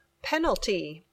Pronunciación